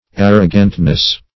Arrogantness \Ar"ro*gant*ness\